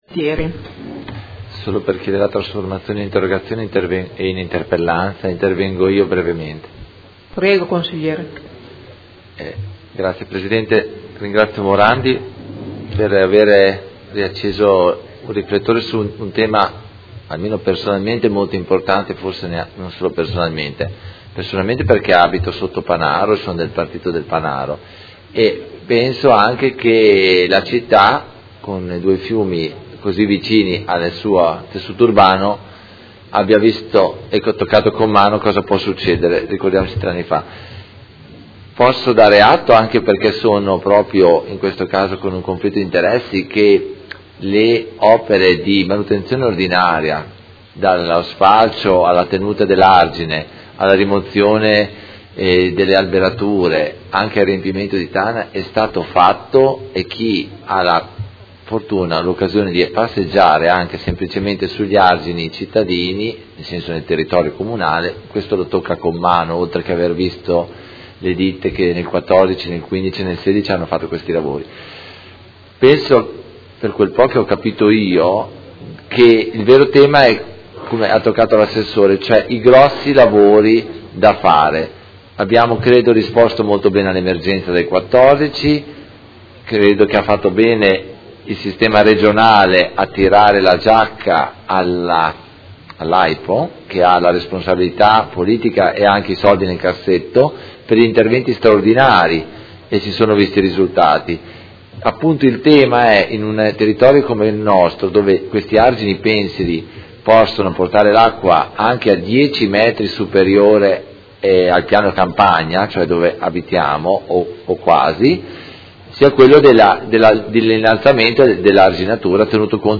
Seduta del 02/03/2017 Dibattito. Interrogazione del Consigliere Morandi (FI) avente per oggetto: La manutenzione degli argini del Secchia e del Panaro è stata completata?